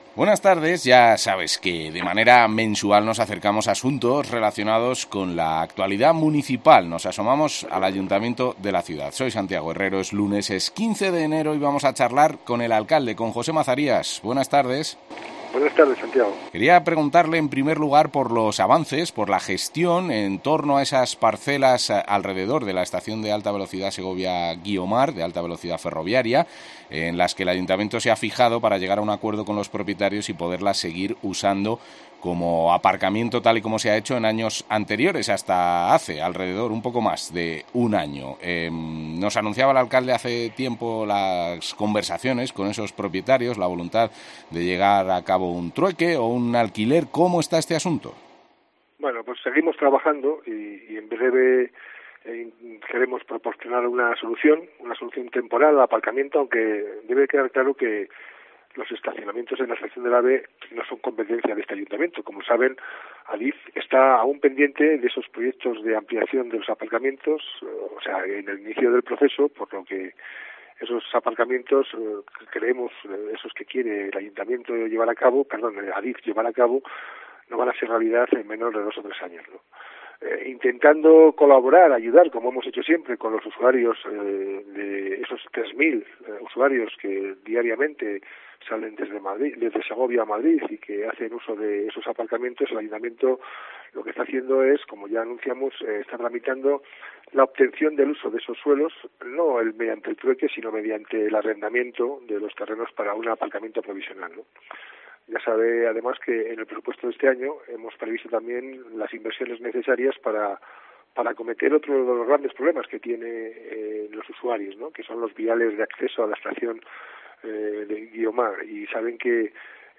José Mazarías, alcalde de Segovia
Lo ha comentado, en los micrófonos de COPE, el alcalde, José Mazarías.